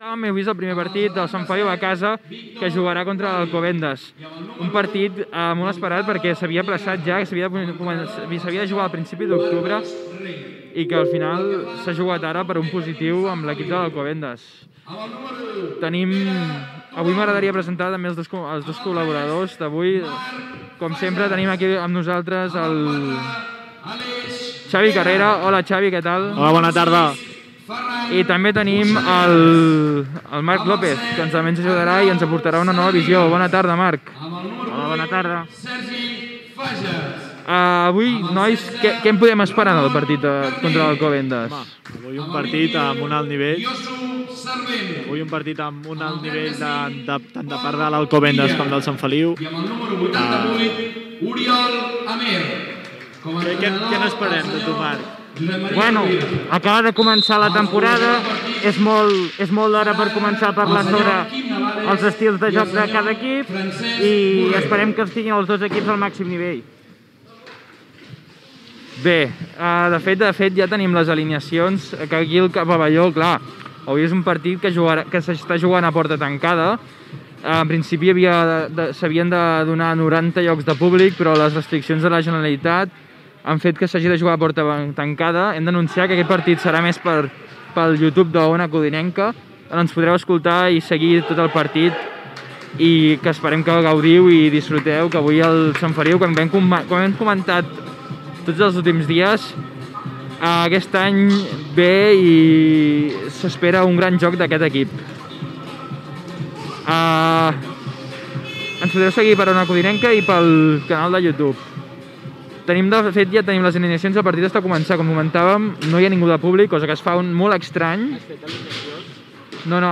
Transmissió del partit a porta tancada d'hoquei patins entre el Club Hoquei Sant Feliu i Club Patin Alcobendas, equip de la transmissió, valoració del partit, identificació, alineacions i primers minuts de joc.
Esportiu